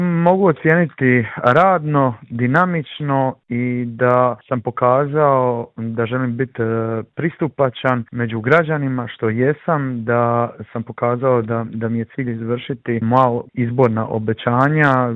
U Intervju Media servisa razgovarali smo s gradonačelnikom Splita Tomislavom Šutom koji nam je prokomentirao aktualnu situaciju i otkrio je li spreman za eventualne izvanredne izbore.